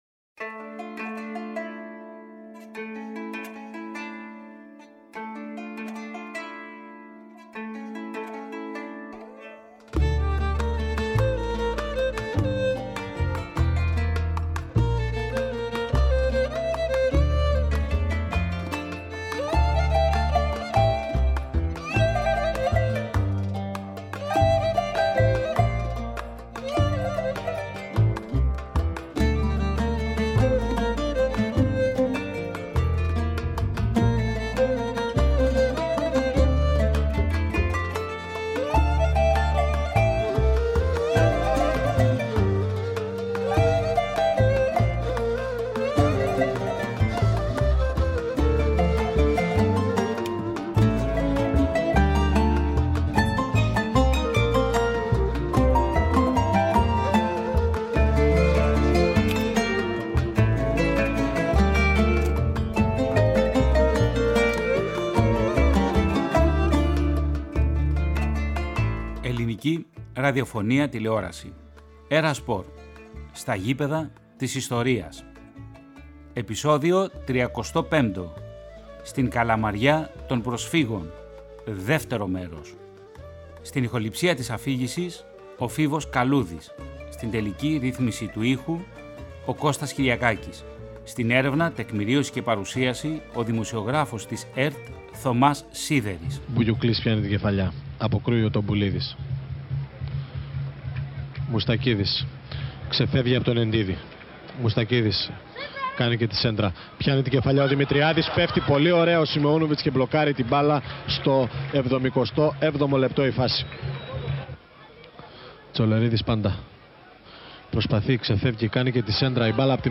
Παράλληλα, εξετάζει την εγκατάσταση των προσφύγων στην Καλαμαριά και το ιστορικό πλαίσιο δημιουργίας του σωματείου, μέσα από συγκλονιστικές μαρτυρίες προσφύγων πρώτης γενιάς.